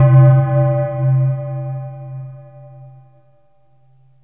AMBIENT ATMOSPHERES-3 0008.wav